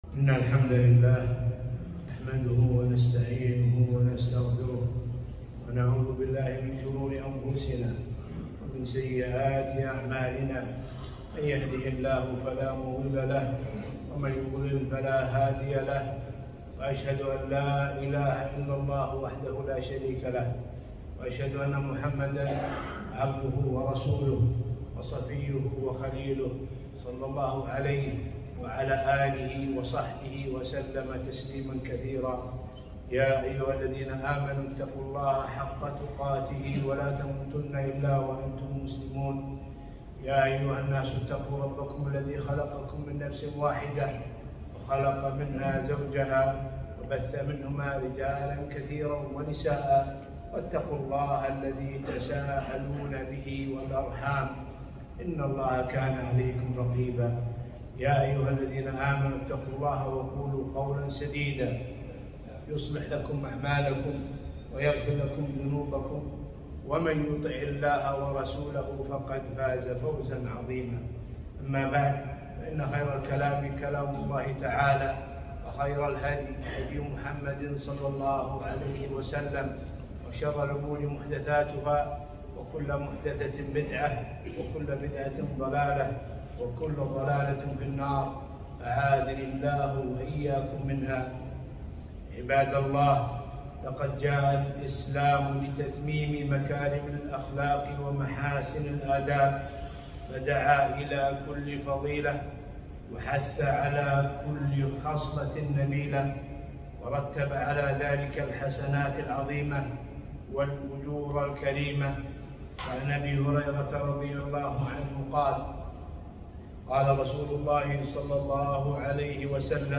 خطبة - فضل المروءة 2-3-1443